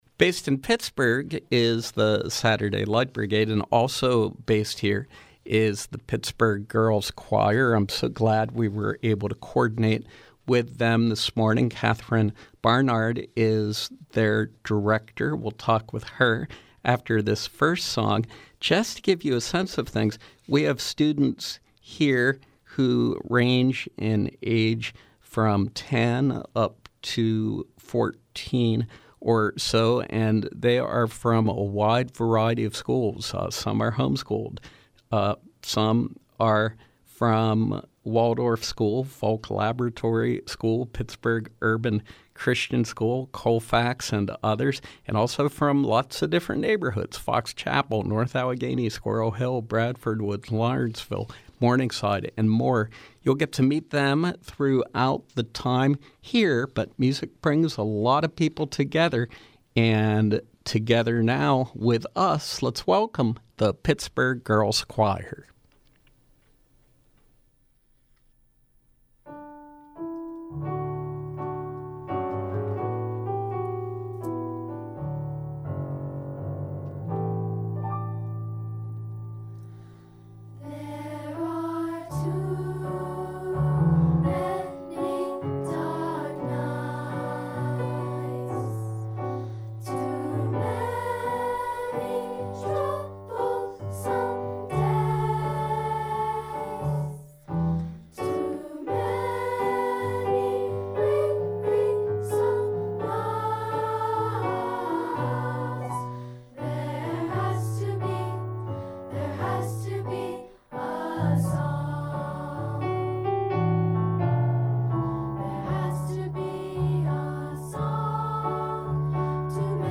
From 05/18/2019: Live performance from Pittsburgh Girls Choir